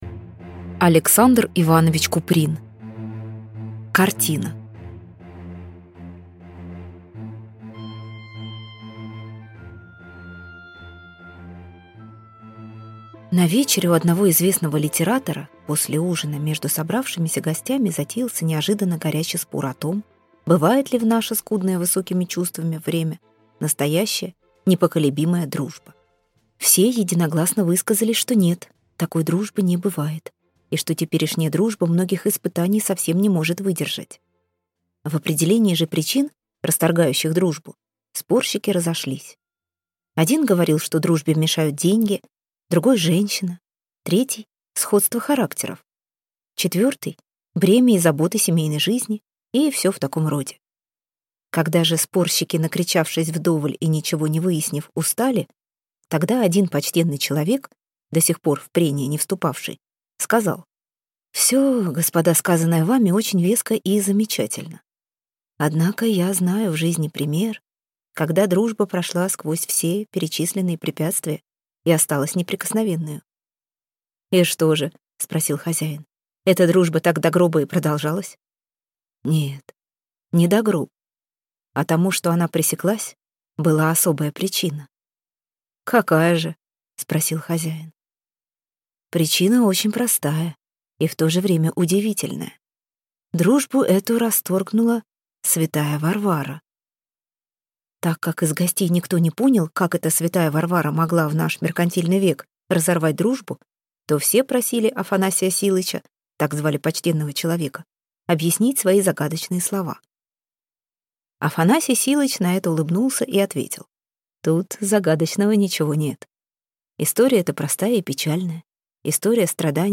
Аудиокнига Картина | Библиотека аудиокниг